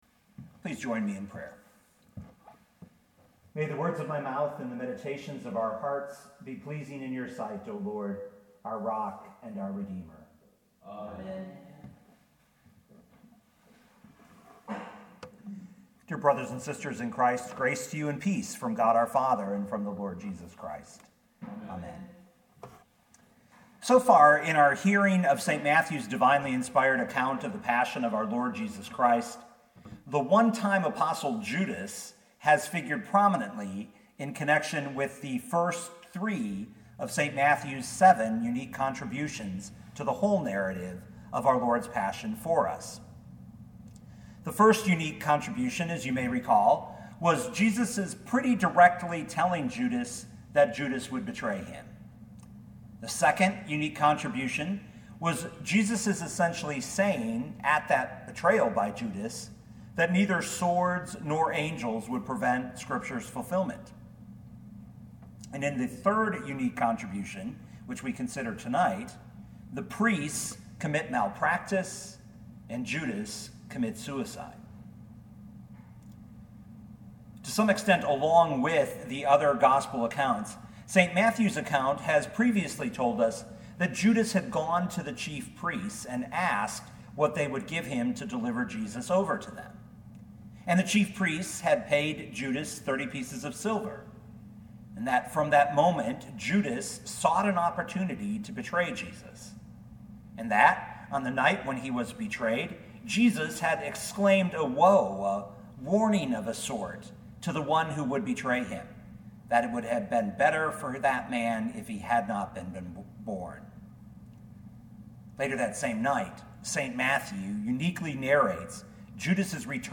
2020 Matthew 27:3-10 Listen to the sermon with the player below, or, download the audio.